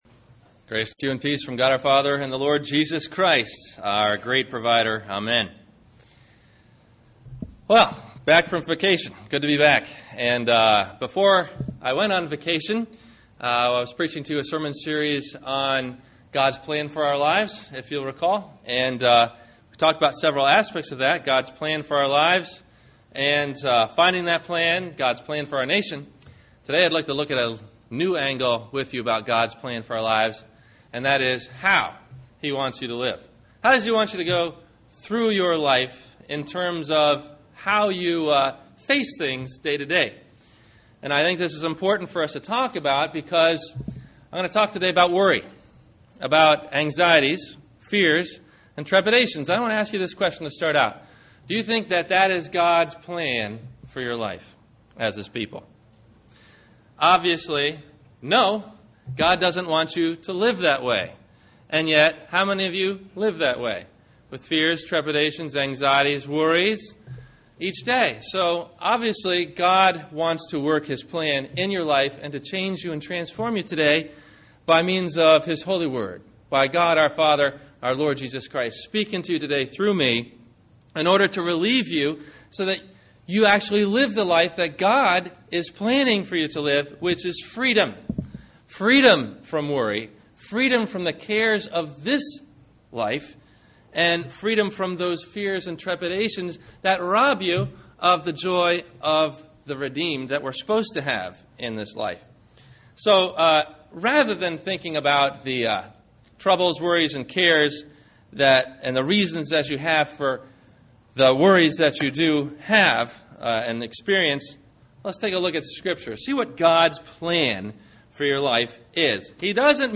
Will God Provide - Sermon - May 17 2009 - Christ Lutheran Cape Canaveral